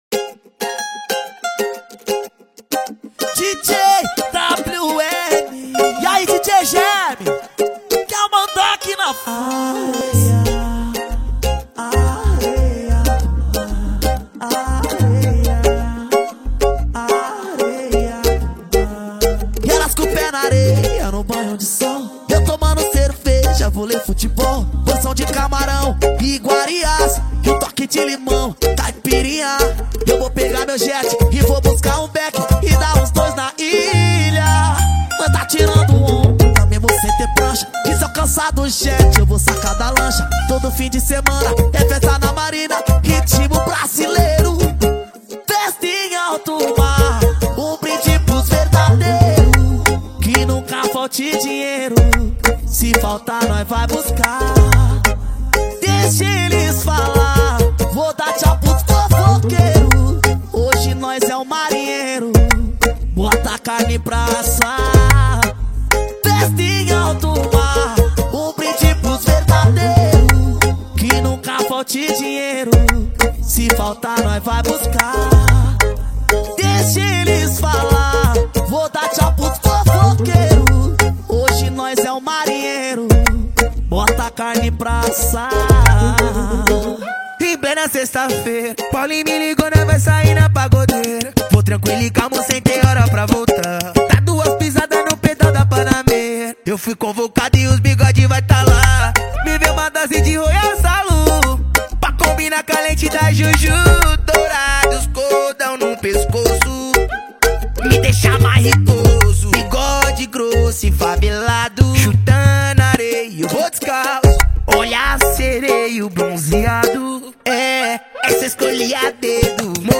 2024-07-25 01:18:16 Gênero: Samba Views